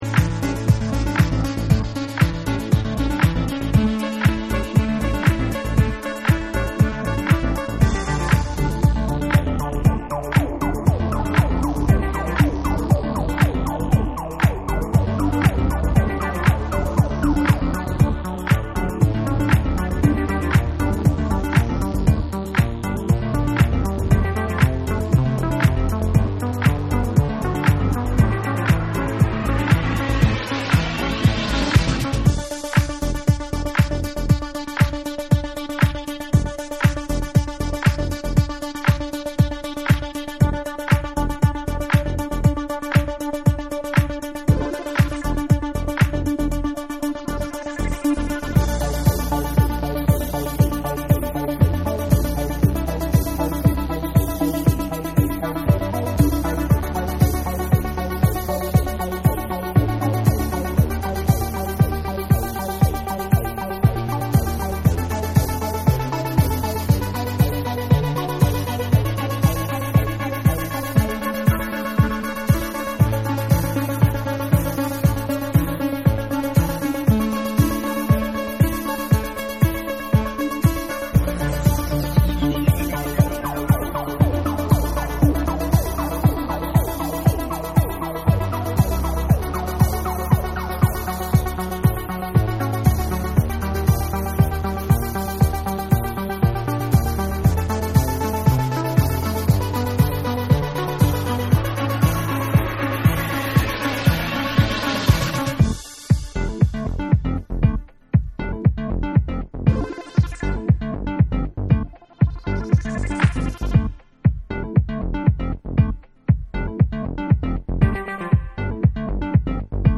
シャッフルするリズムに飛び系効果音が炸裂する変態ポスト・パンクナンバー
ざらついた質感がナイスなシカゴ・オマージュ・チューン
TECHNO & HOUSE